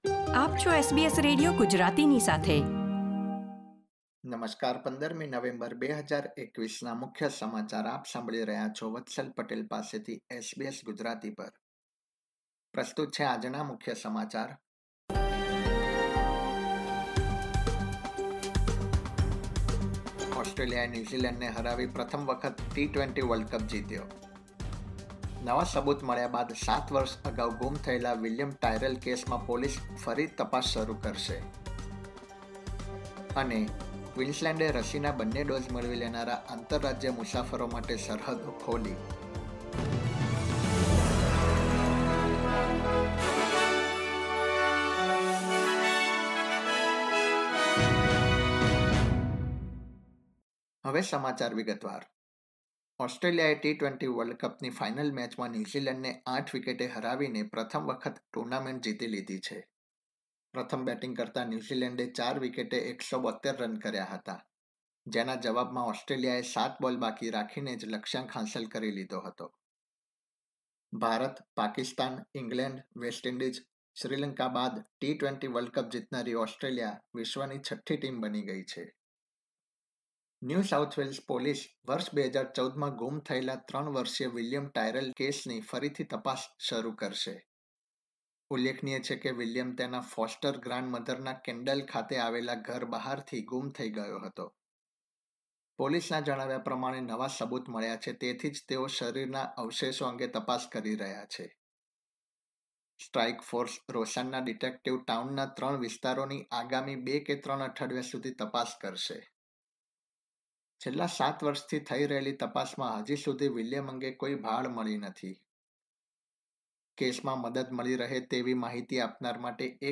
gujarati_1511_newsbulletin.mp3